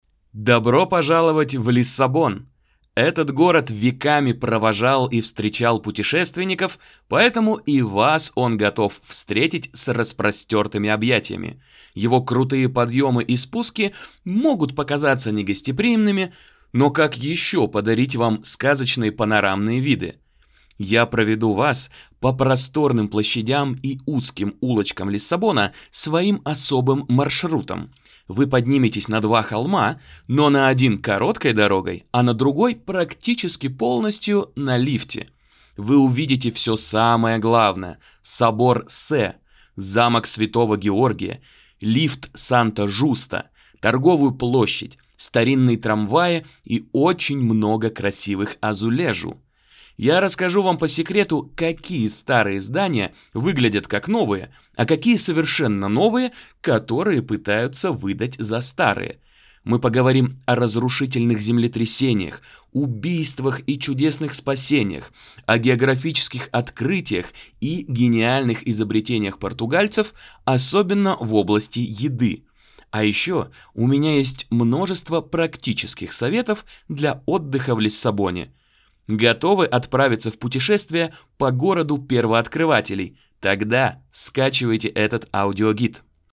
Лиссабон в ритме шагов — аудиогид TouringBee по столице Португалии